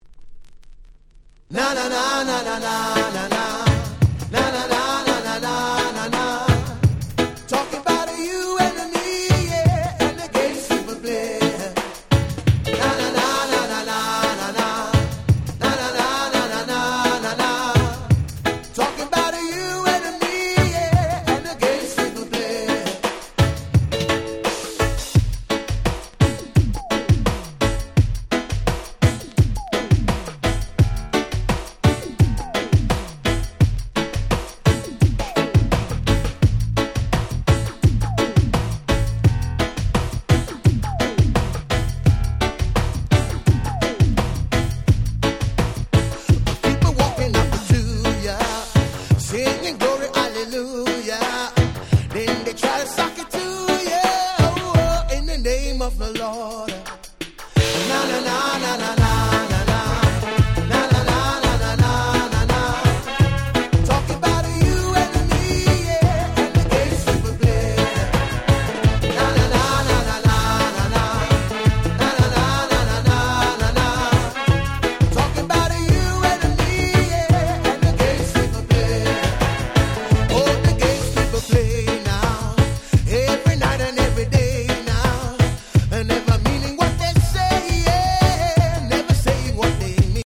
このコンピの特徴は「音が良い」事。
夏にぴったりなPop Reggaeヒット4曲収録で超お得！